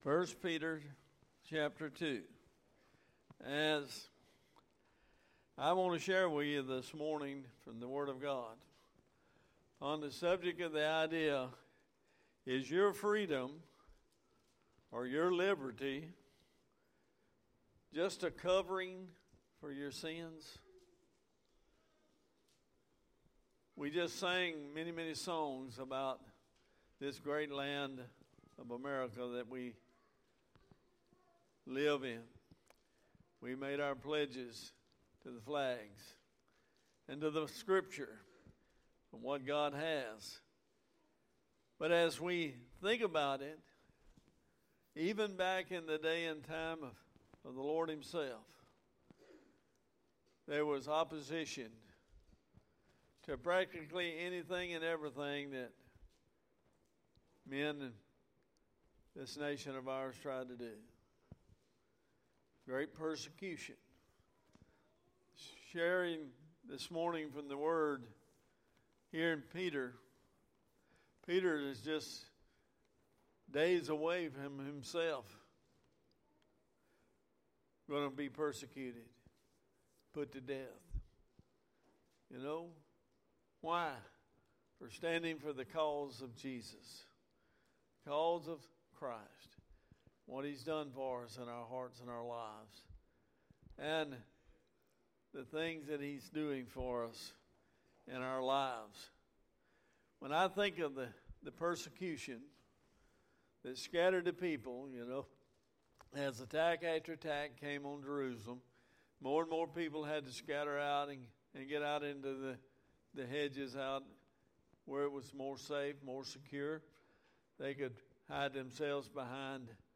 Sermons | Bexley Baptist Church